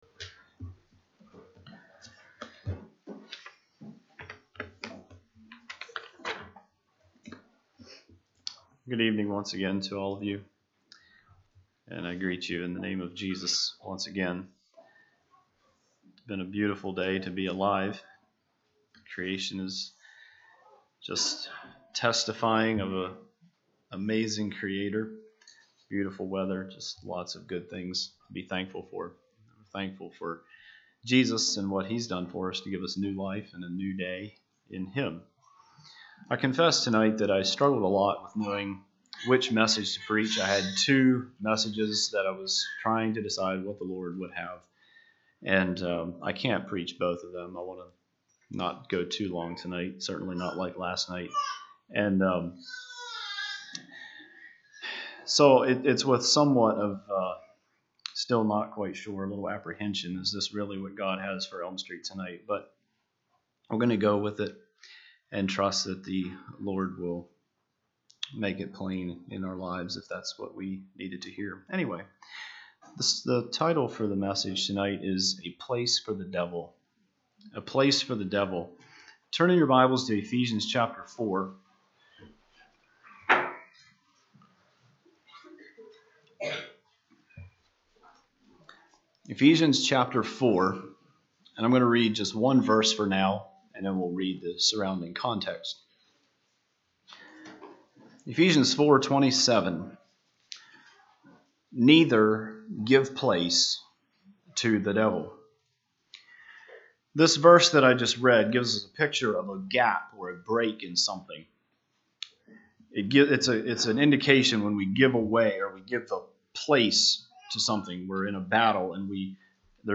Sermon
Evangelistic